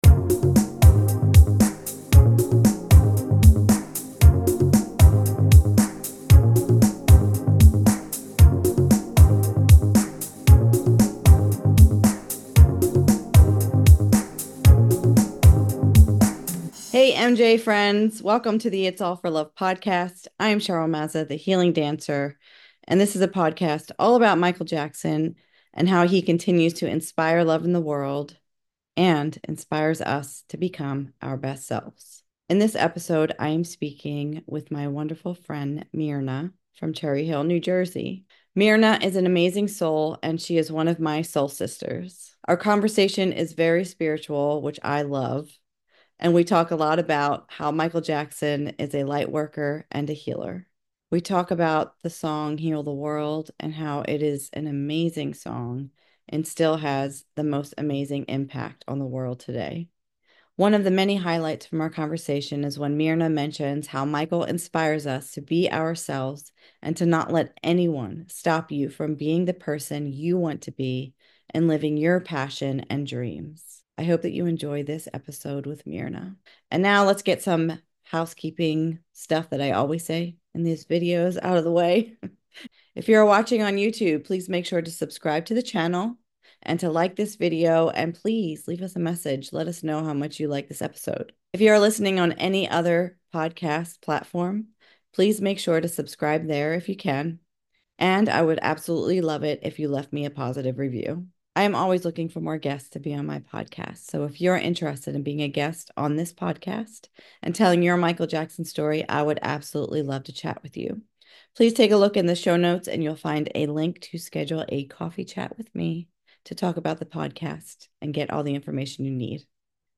Our conversation is very spiritual and we talk a lot about how Michael is a lightworker and healer. We chat about the song Heal the World and how amazing this song is, and how it is still so powerful today.